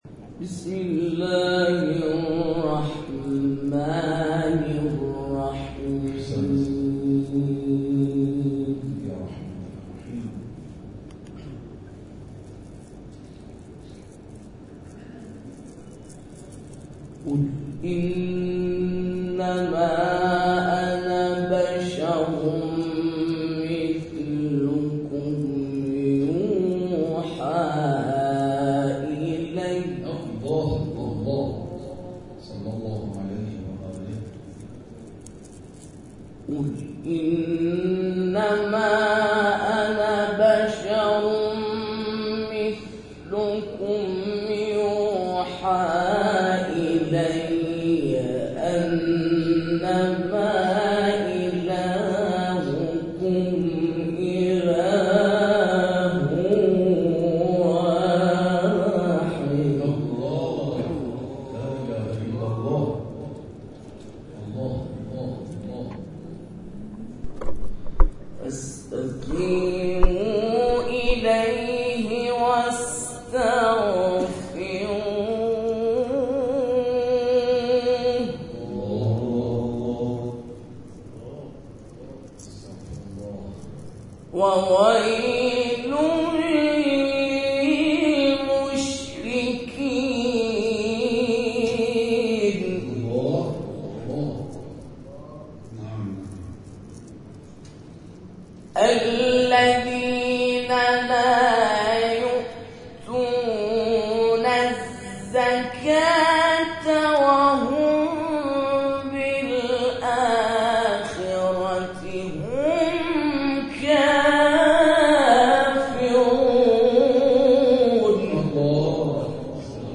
مسجد امام حسن مجتبی(ع)، واقع در خیابان مصطفی خمینی، چهارراه سیروس، پانزده خرداد شرقی، شب گذشته، 22 خرداد، از ساعت 23 الی 2:30 بامداد با حضور قاریان از نقاط مختلف تهران برگزار شد.
در ادامه، تلاوت‌ها و گزارش تصویری این جلسه ارائه می‌شود.